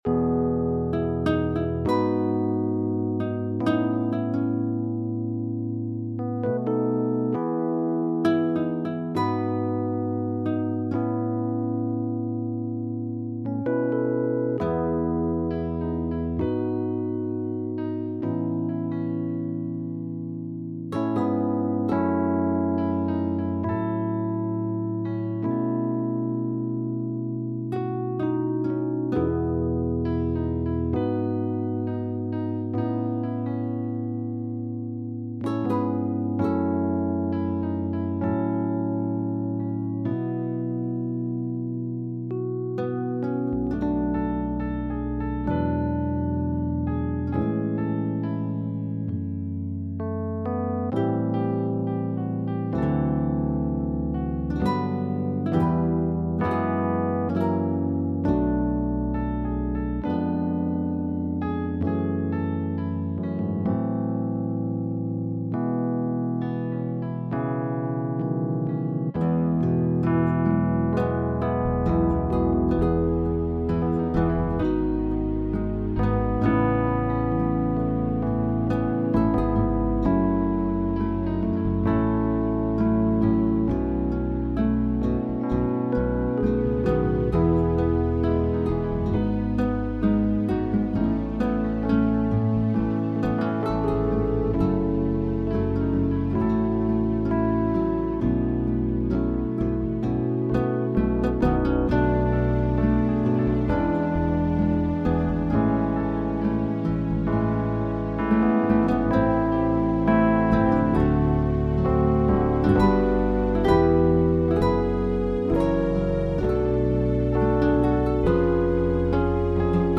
Amazing Place - Backing Track